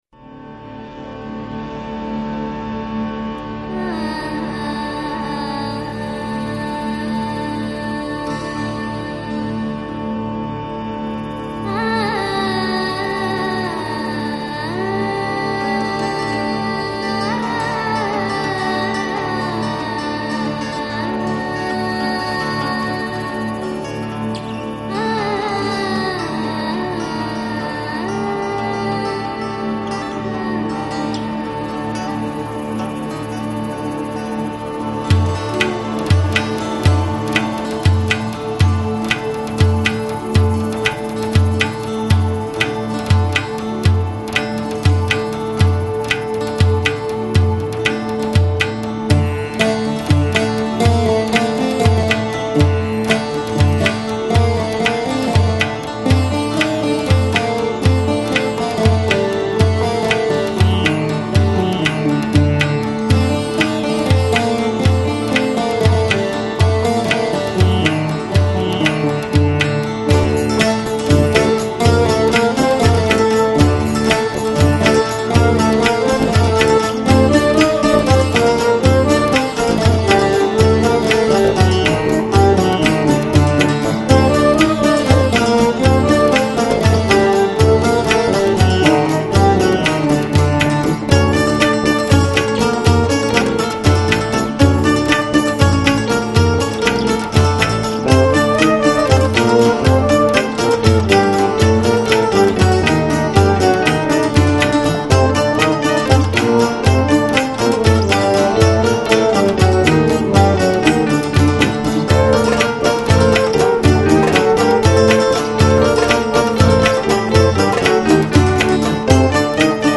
Lounge, Chill Out, Ambient, Downtempo, World